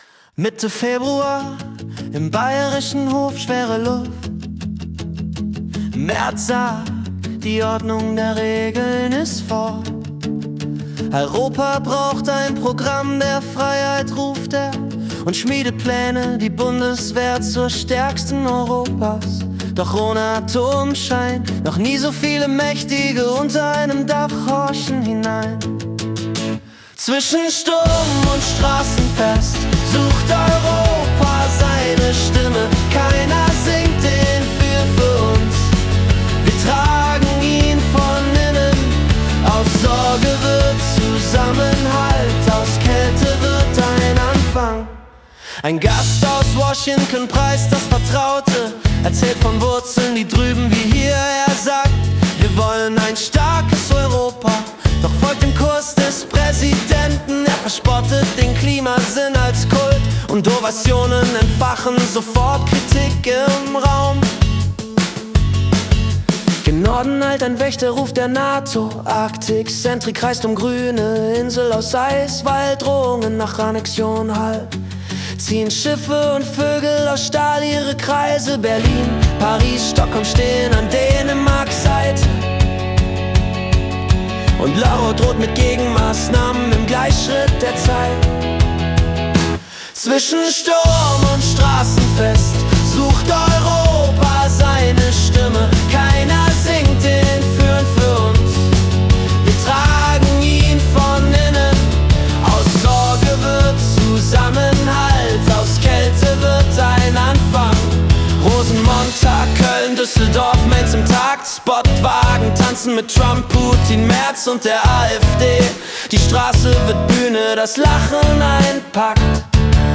Februar 2026 als Singer-Songwriter-Song interpretiert.